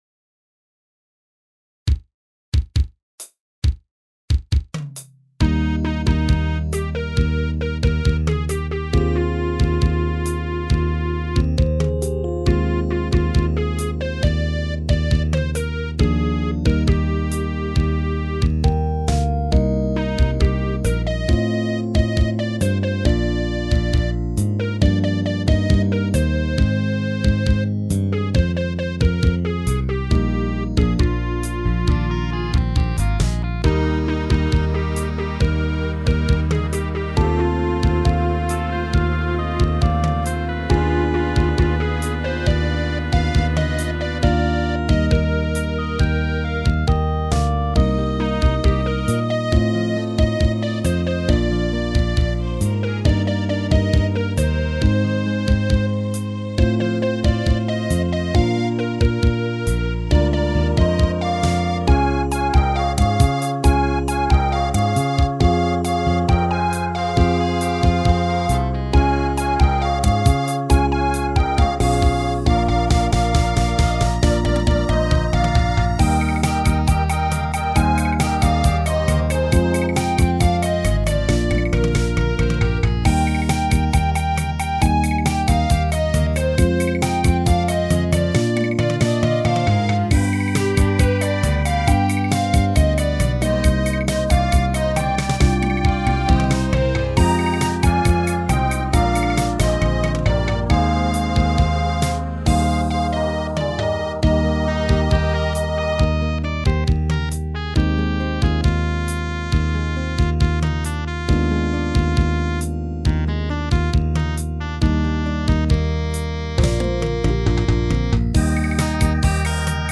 全体的にボーカル陣が高音だがやむなし。